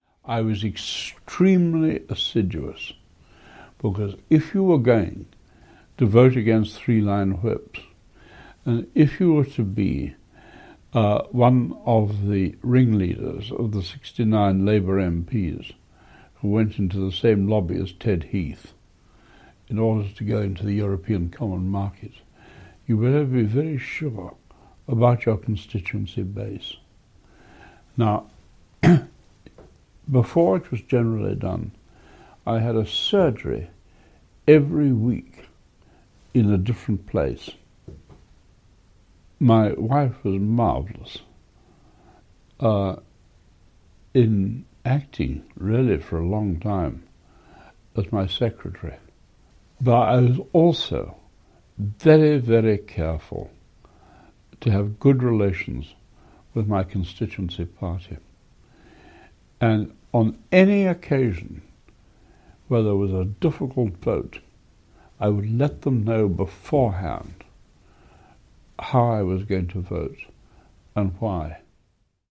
In this clip former Labour MP Tam Dalyell discusses how his constituency party dealt with his reputation for independence.